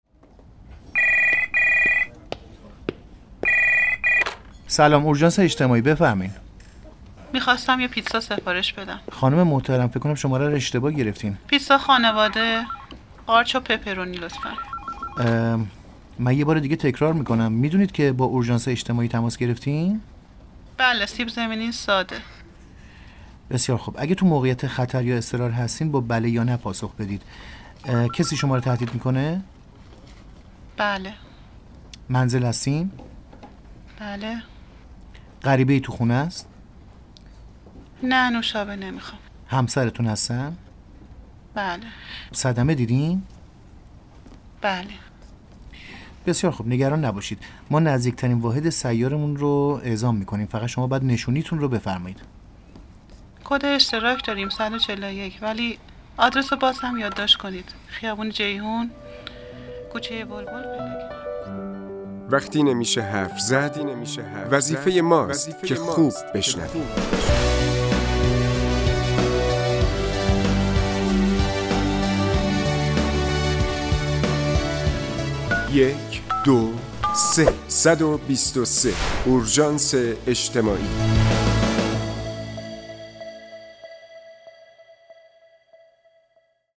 PSA اورژانس اجتماعی